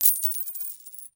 Coins Thrown Sound
household
Coins Thrown